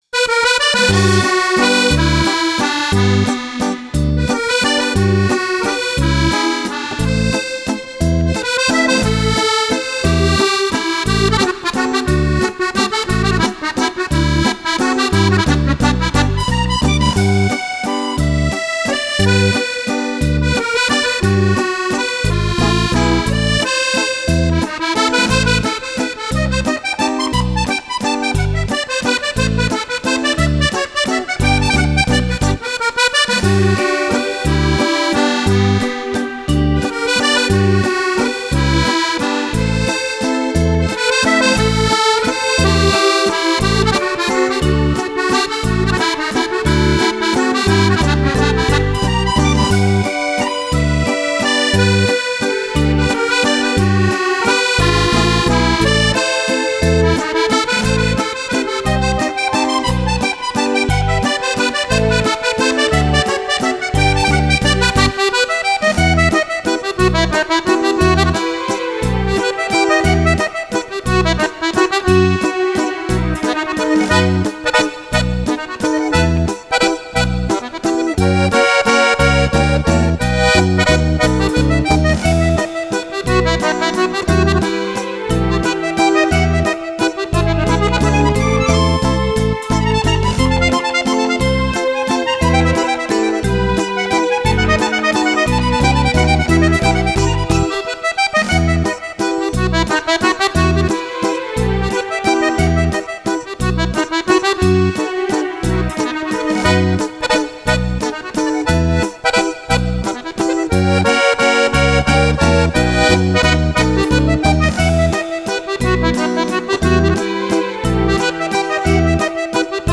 Valzer
14 ballabili per fisarmonica solista
Registrato in Home Recording